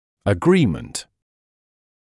[ə’griːmənt][э’гриiːмэнт]согласие; взаимное согласие; единое мнение; соглашение